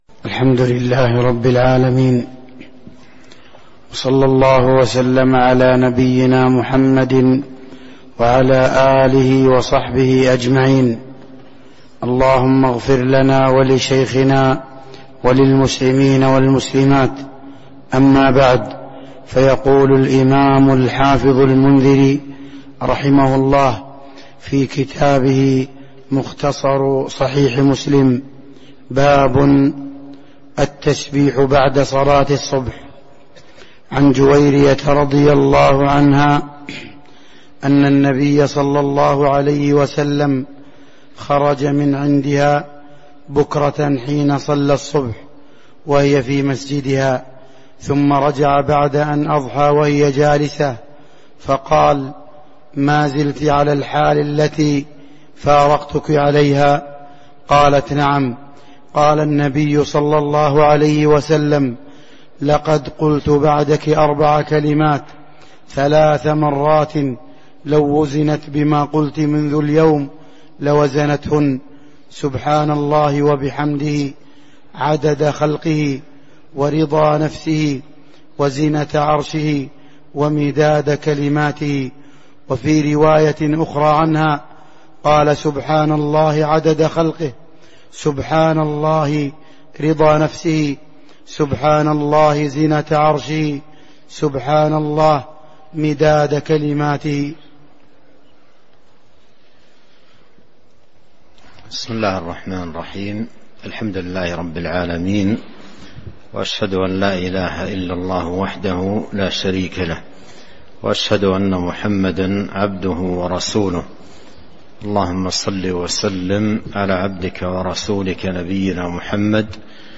تاريخ النشر ٦ ذو الحجة ١٤٤٣ هـ المكان: المسجد النبوي الشيخ: فضيلة الشيخ عبد الرزاق بن عبد المحسن البدر فضيلة الشيخ عبد الرزاق بن عبد المحسن البدر باب التسبيح بعد صلاة الصبح (05)